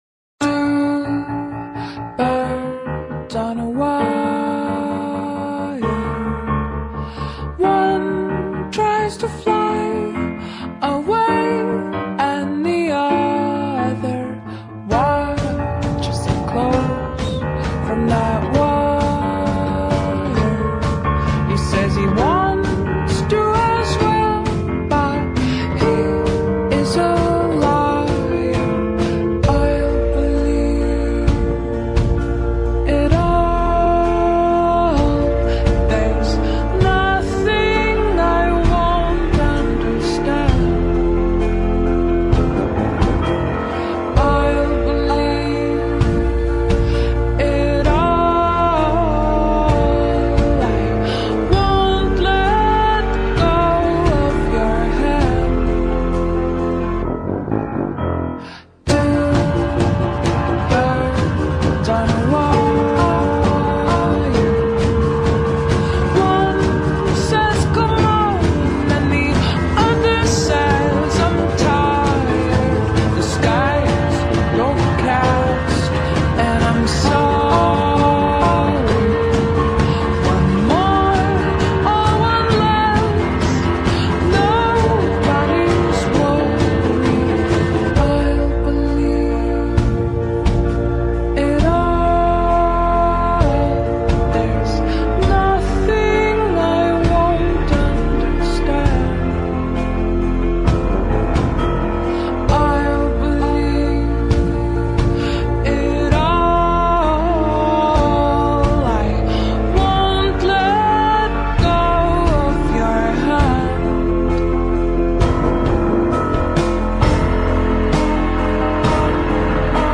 slowed down